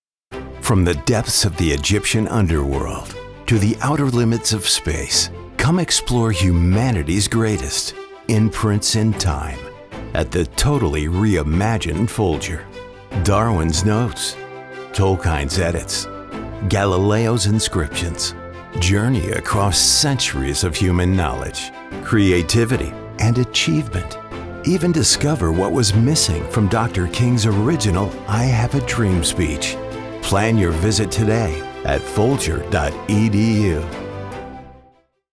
mid-Atlantic, North American English
Middle Aged
His voice is described as warm, textured, engaging, and dynamic, conveying a wide range of emotions and tones that resonate with audiences.